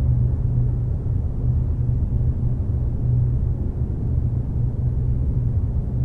factory control room.ogg